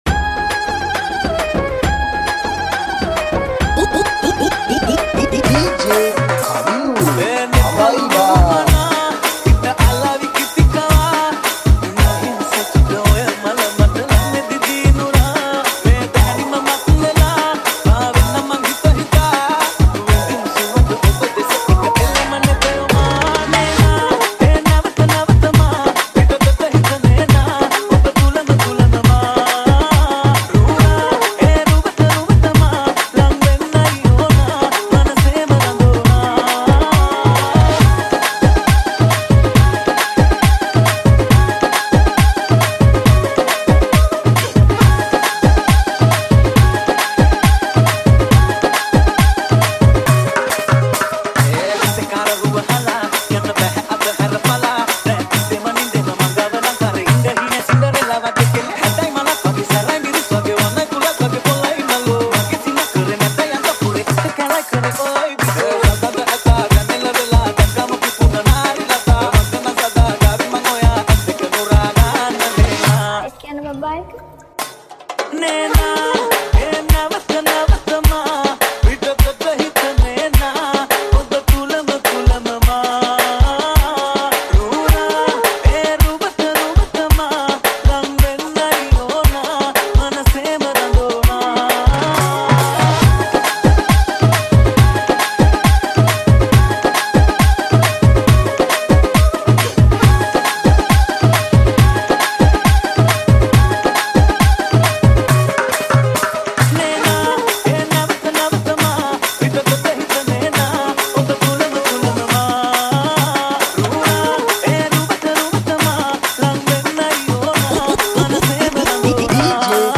High quality Sri Lankan remix MP3 (2.3).